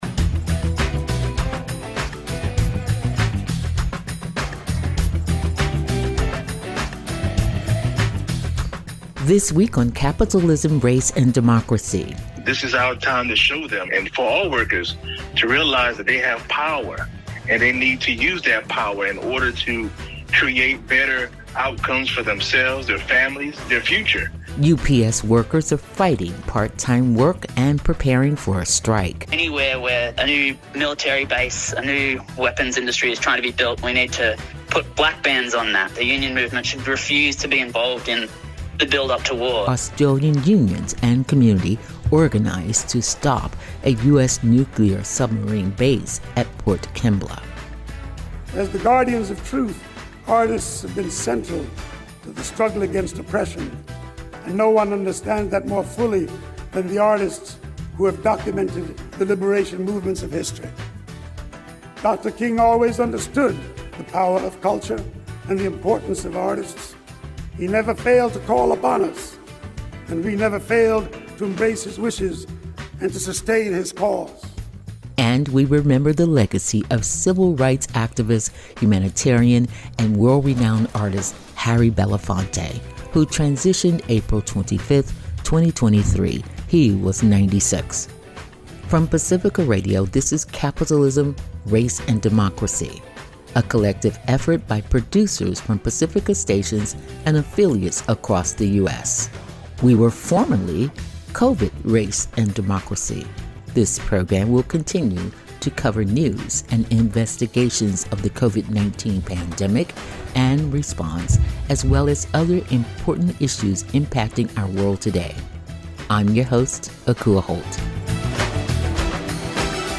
From Pacifica Radio, this is Capitalism, Race, & Democracy, (formerly Covid, Race, and Democracy), a collective effort by producers from Pacifica stations and affiliates across the US.